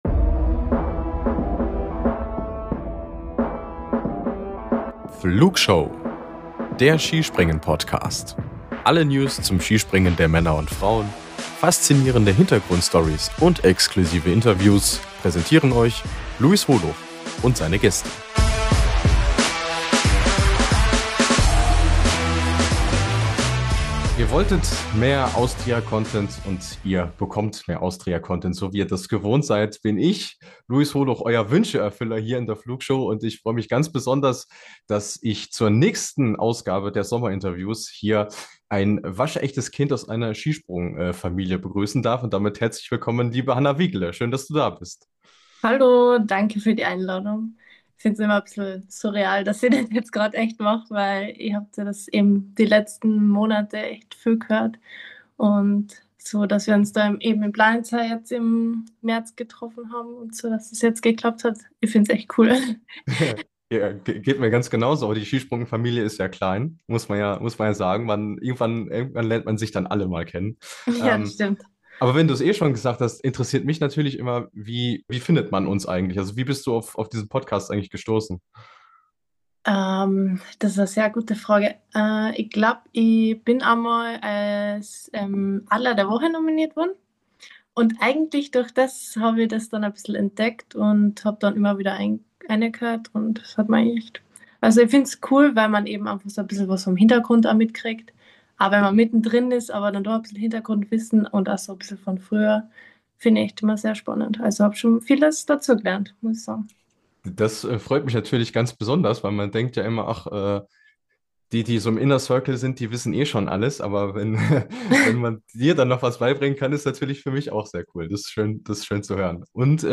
Interview ~ Wintersport Podcast